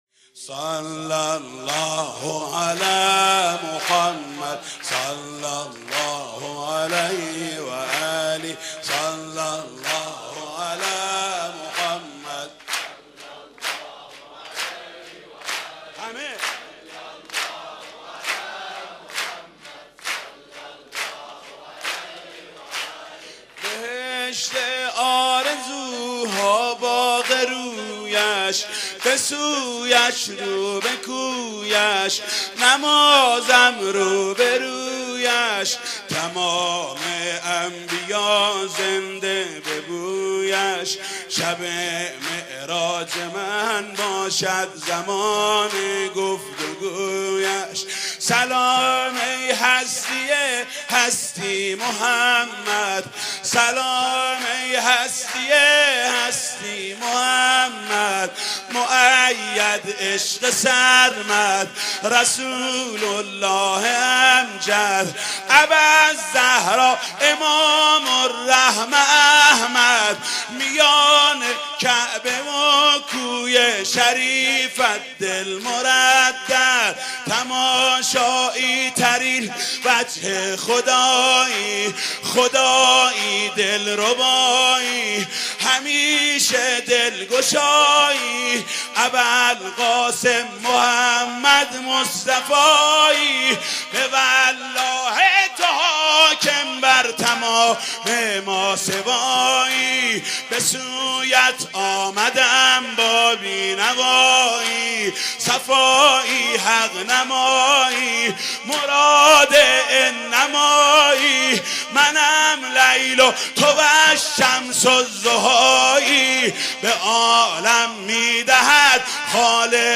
صوت/ مداحی کریمی برای ولادت پیامبر(ص)
مولودی ولادت حضرت رسول(ص) با صدای محمود کریمی را بشنوید.